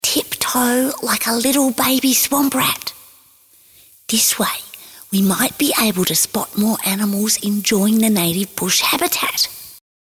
Warm, fun, engaging and natural Lucinda has worked as voice artist extensively for over 30 years both here and in the UK in various Animations including “Koala Brothers”, BBC Radio Drama “Our Father Who Art In A Tree”, ”We Need to Talk About Kevin”
Narration
Middle Aged